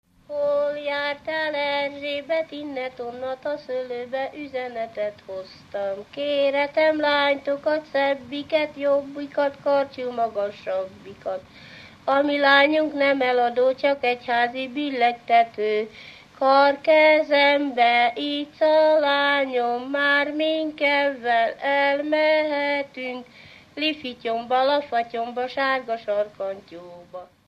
Alföld - Pest-Pilis-Solt-Kiskun vm. - Kiskunmajsa
ének
Műfaj: Gyermekjáték
Stílus: 8. Újszerű kisambitusú dallamok
Kadencia: 5 (4) 1 1